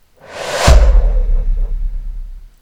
Impact Transition Sound Effect Free Download
Impact Transition